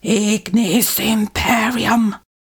mandrake fvttdata/Data/modules/psfx/library/incantations/older-female/fire-spells/ignis-imperium
ignis-imperium-whisper.ogg